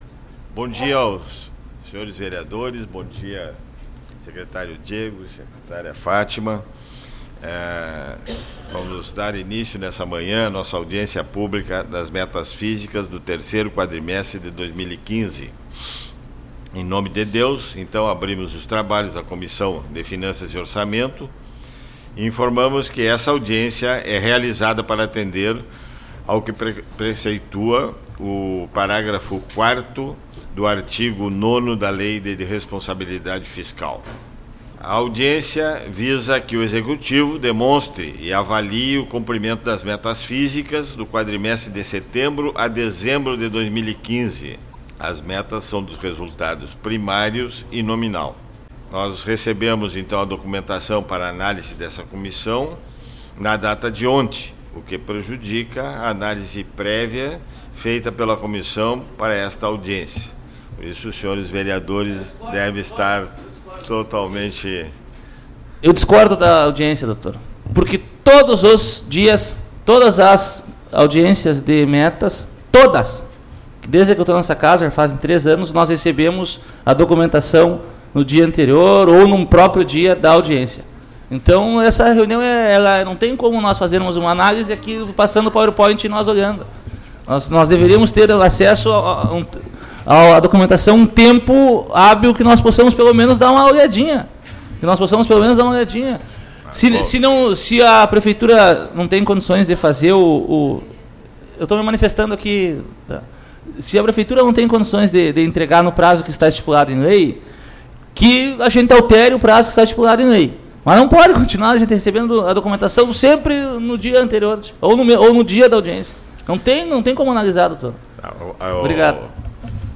26/02 - Audiência Pública - Apres. do Executivo das Metas Fiscais - 3º Quadrimestre 2015